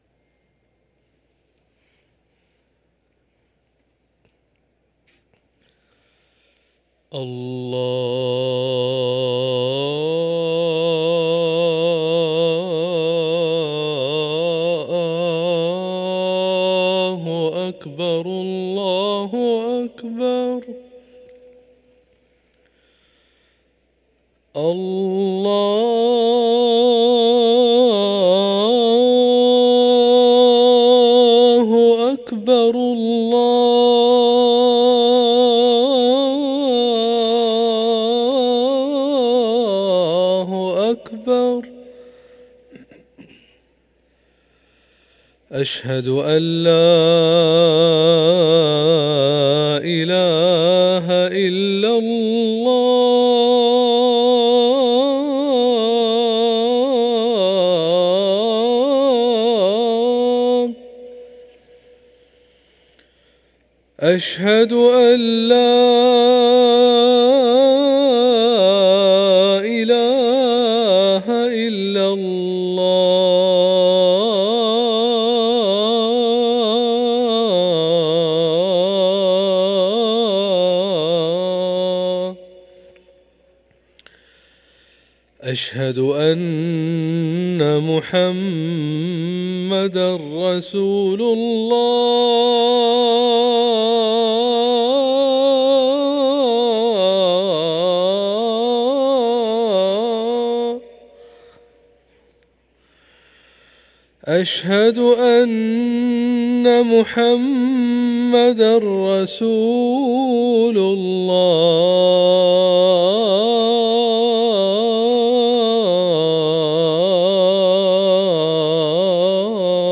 أذان الفجر للمؤذن